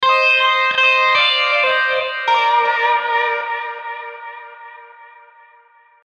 Tag: 80 bpm Folk Loops Guitar Electric Loops 1.01 MB wav Key : Unknown